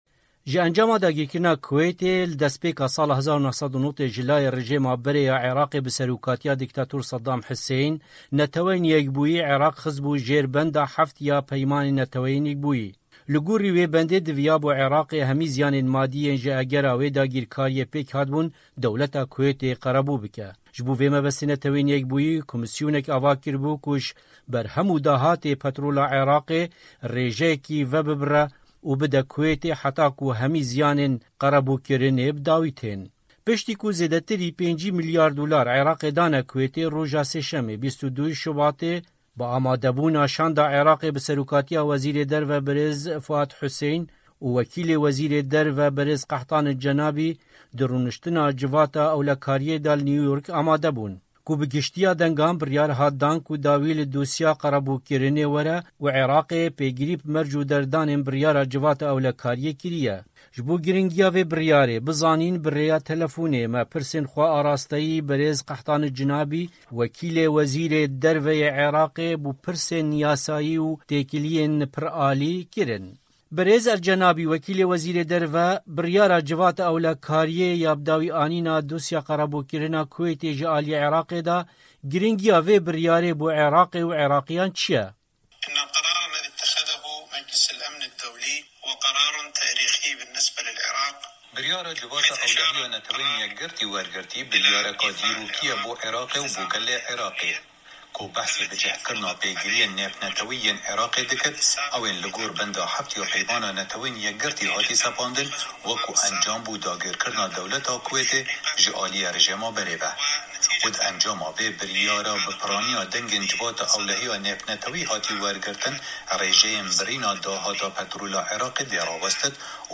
Hevpeyvîn li Gel Kahtan Al Janab Wekîlî Wezîrê Derve Yê Îraqê